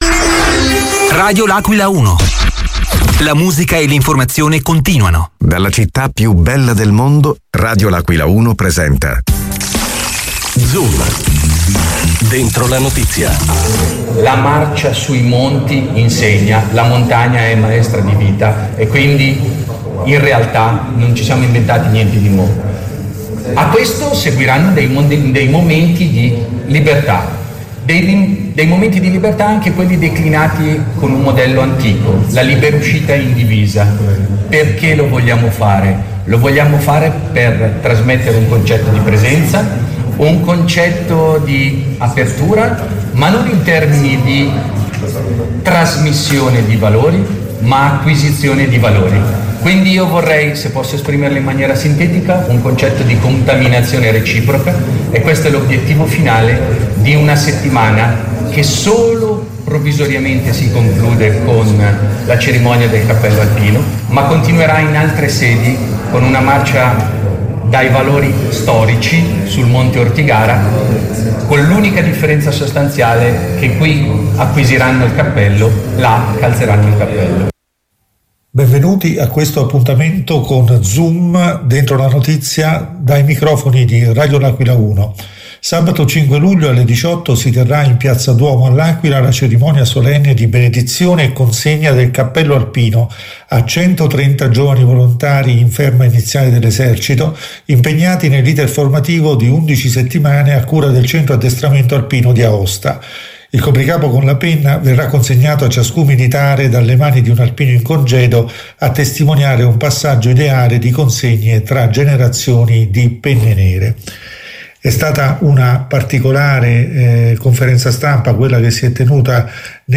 Nella conferenza stampa di presentazione di questa cerimonia, che si è svolta Venerdì 13 Giugno scorso a Palazzo Margherita sede della Municipalità aquilana,